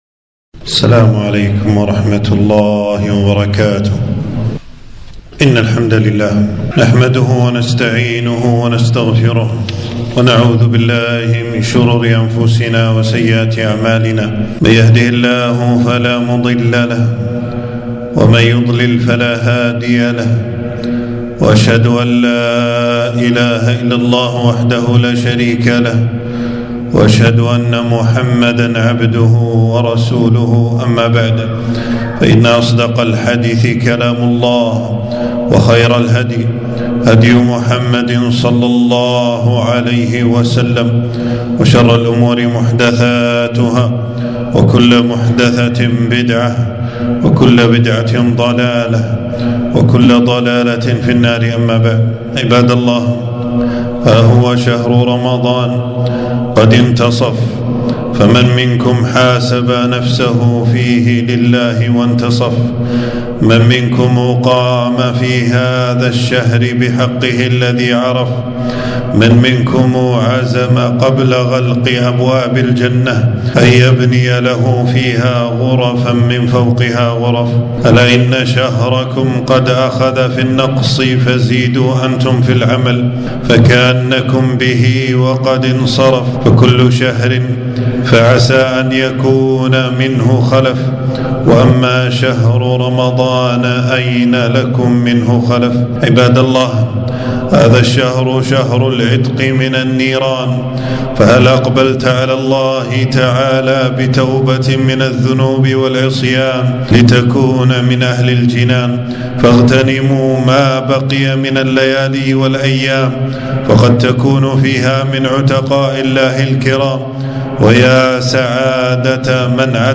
خطبة - مضى نصف رمضان فهل من مدكر!